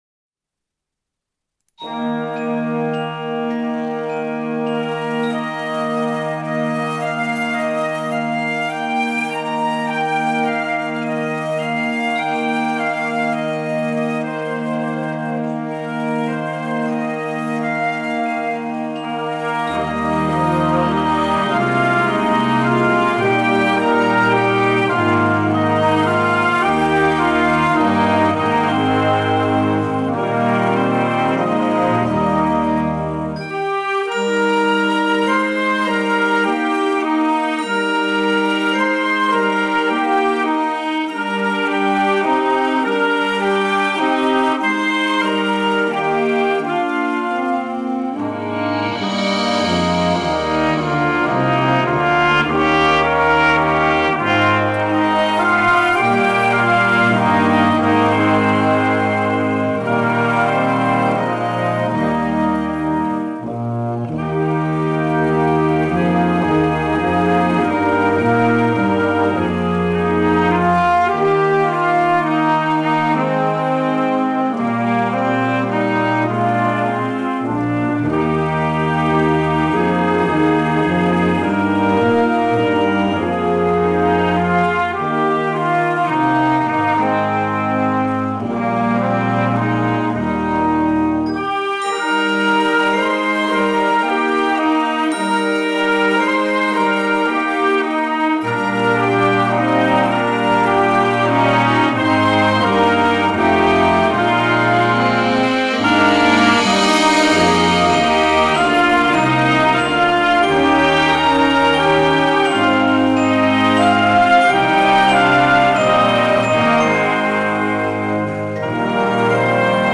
フレックス・バンド，アンサンブル曲の参考音源